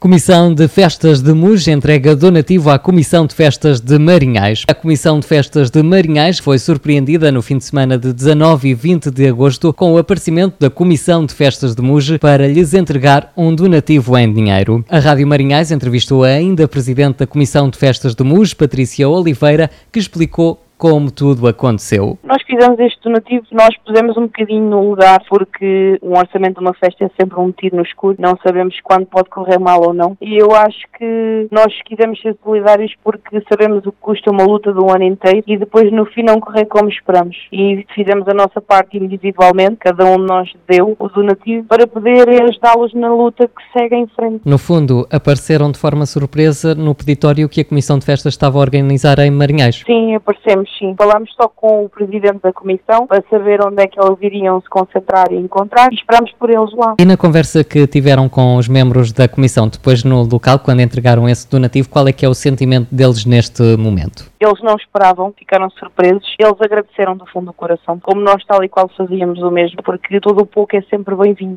Em entrevista à MarinhaisFM